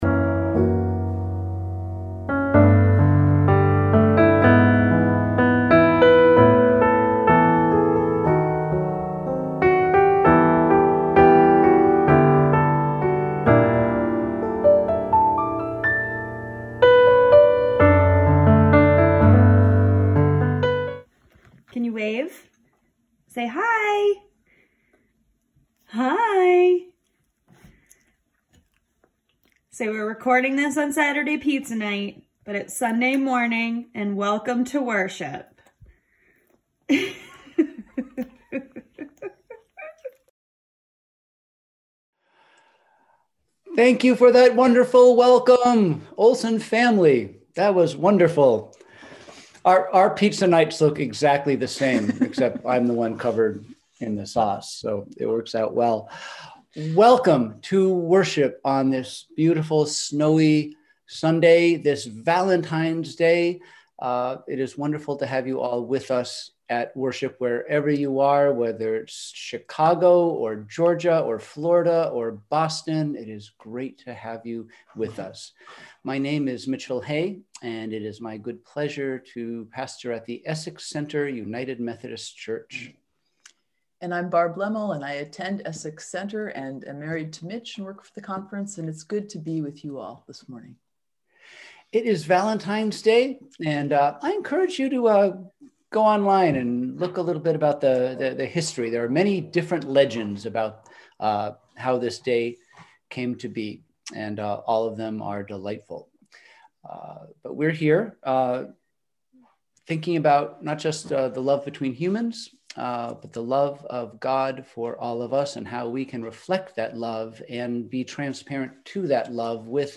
We held virtual worship on Sunday, February 14, 2021 at 10:00AM!
Sermons